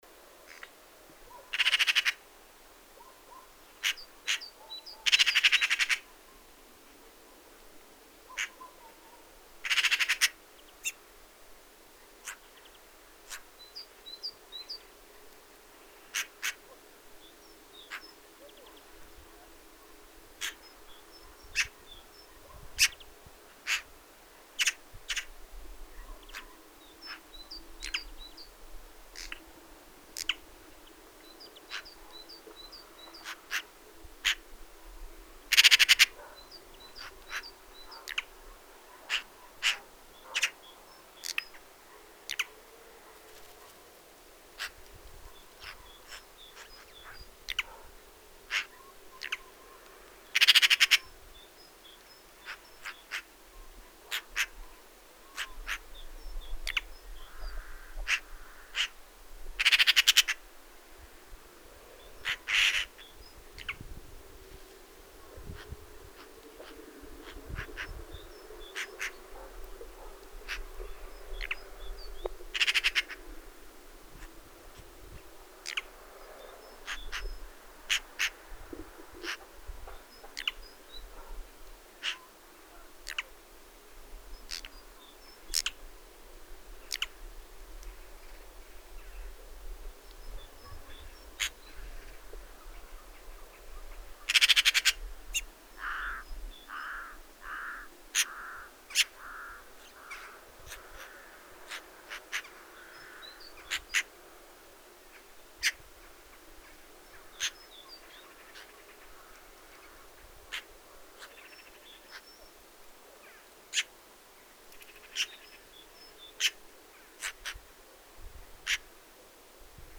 PIE BAVARDE.mp3